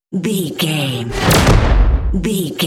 Dramatic whoosh to hit trailer
Sound Effects
Atonal
dark
intense
tension
woosh to hit